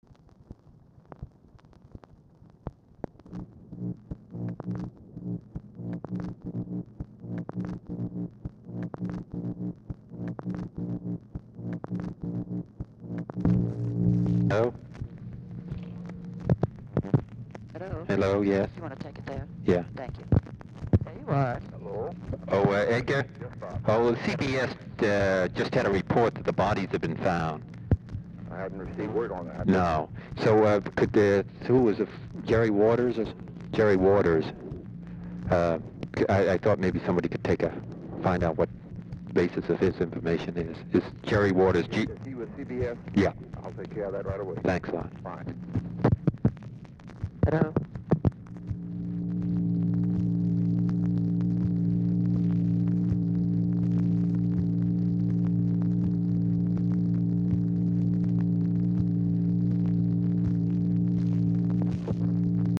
Telephone conversation # 3876, sound recording, ROBERT KENNEDY and J. EDGAR HOOVER, 6/23/1964, 8:00PM | Discover LBJ
Format Dictation belt
Specific Item Type Telephone conversation